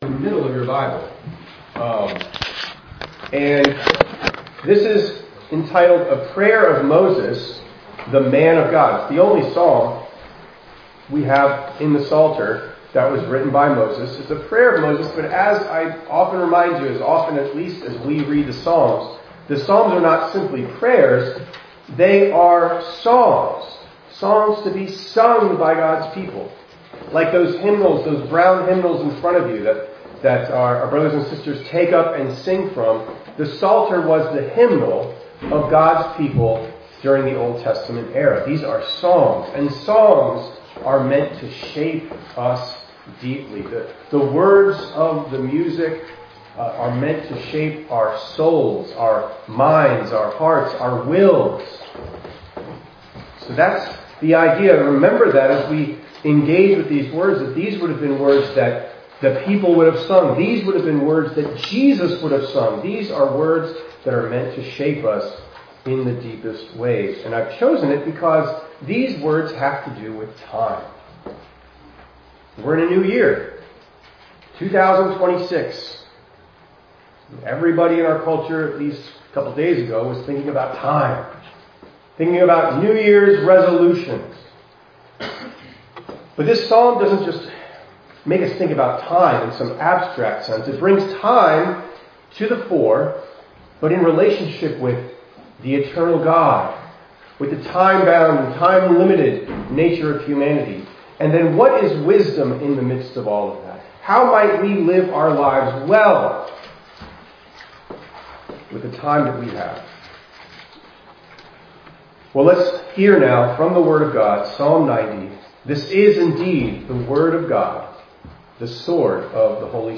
1_4_26_ENG_Sermon.mp3